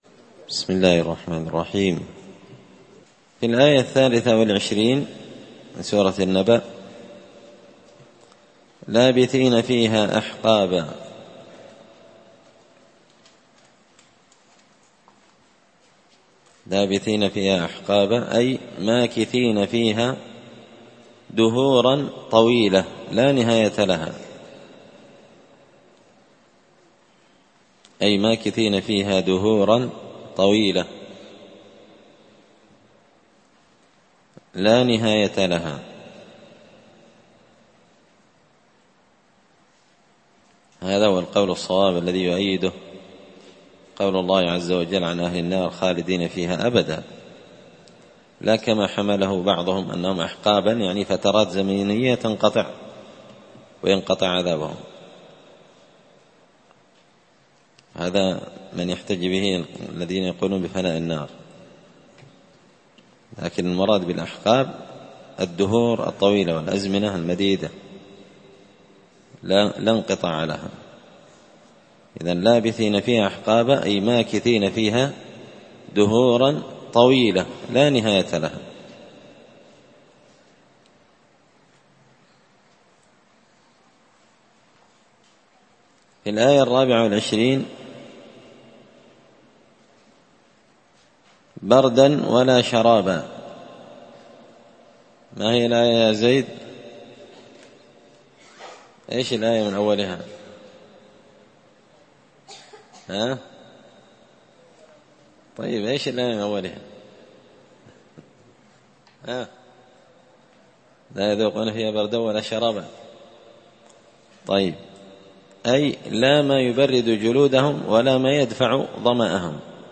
زبدة الأقوال في غريب كلام المتعال الدرس الثاني (2)